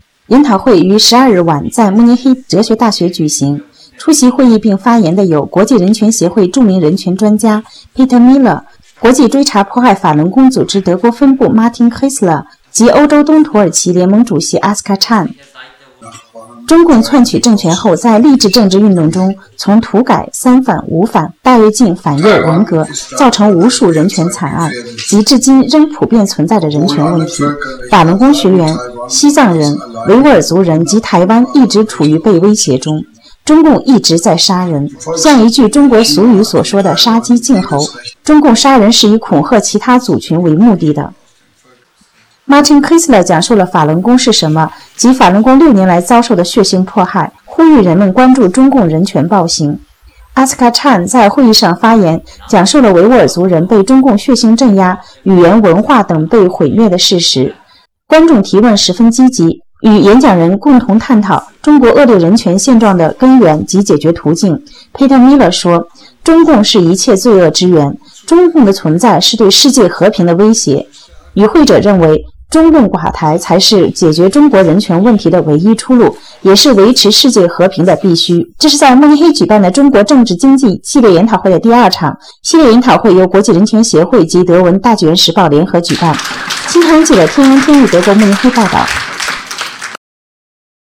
VORTRAG, FILM, DISKUSSION ZUM INTERNATIONALEN TAG DER MENSCHENRECHTE
Ort: Hochschule für Philosophie